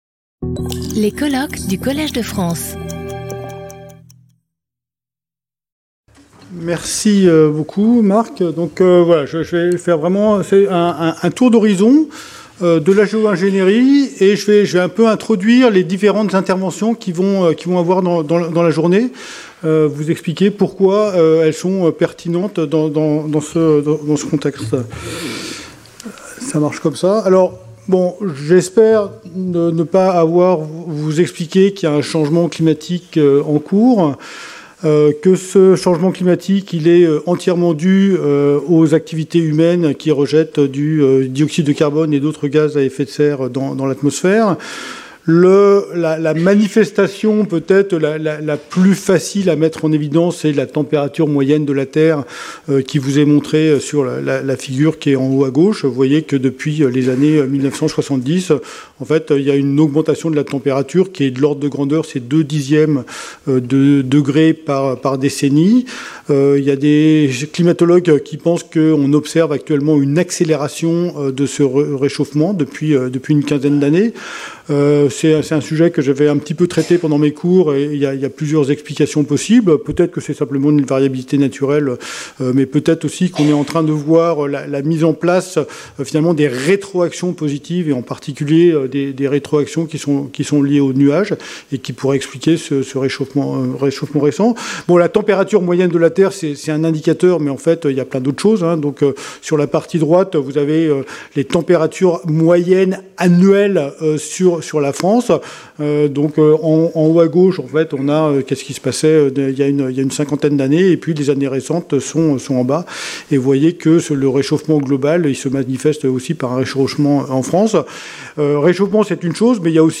Symposium 15 May 2025 09:30 to 10:00